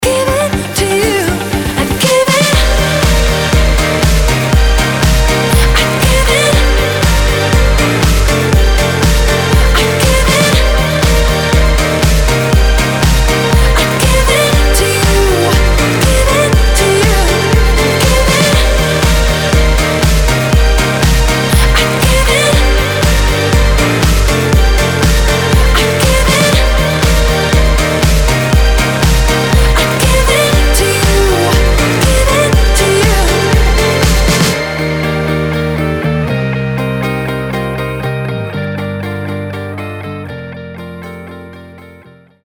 • Качество: 320, Stereo
Новый танцевальный сингл